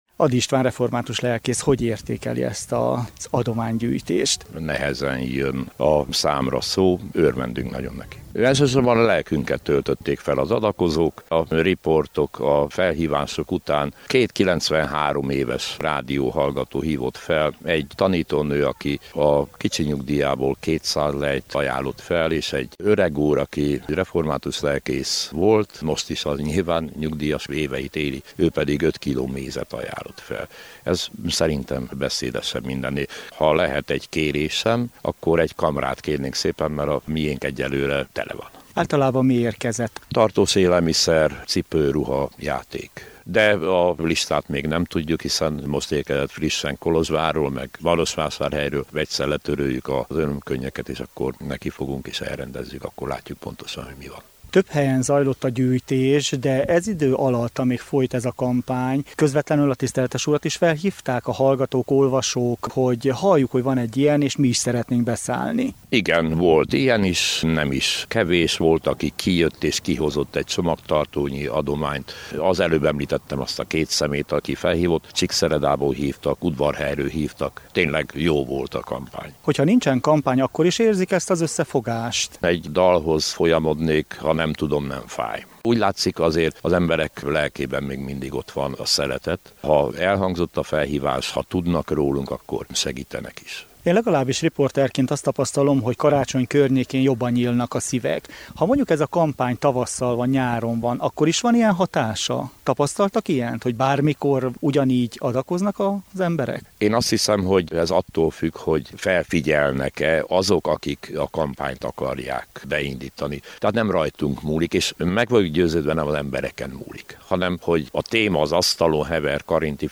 riportja